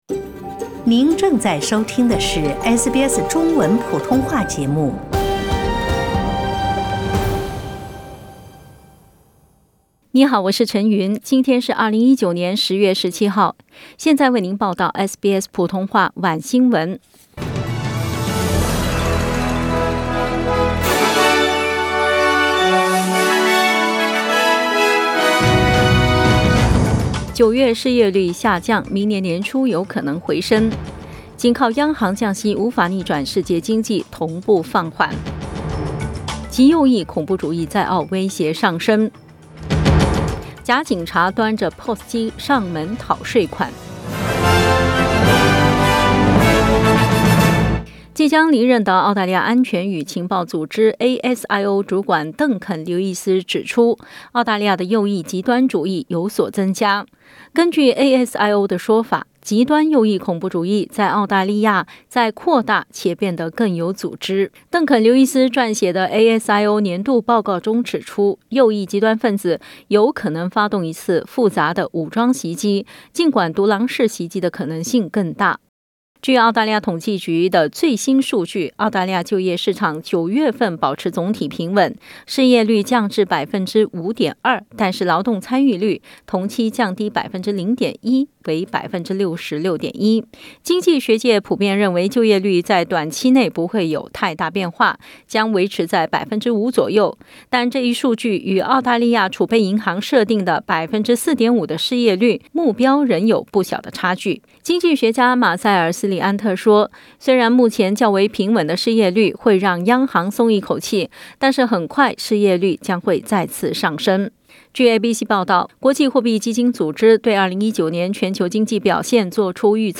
SBS晚新闻（2019年10月17日）